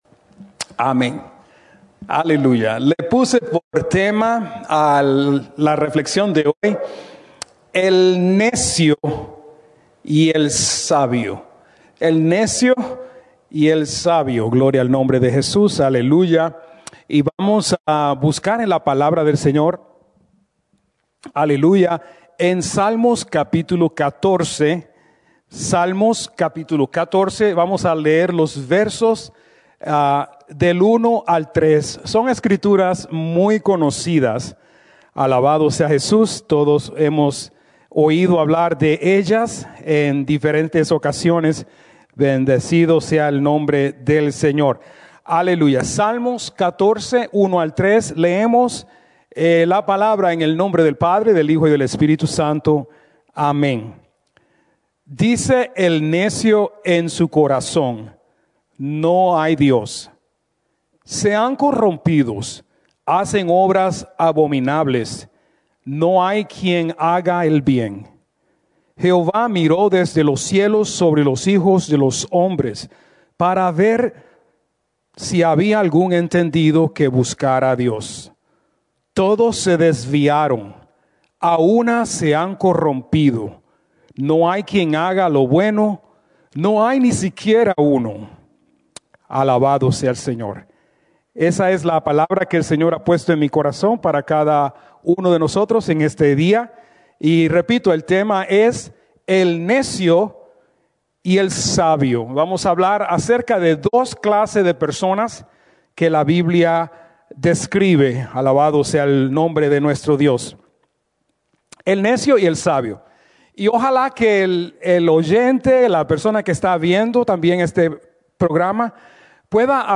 Mensaje
en la Iglesia Misión Evangélica en Souderton, PA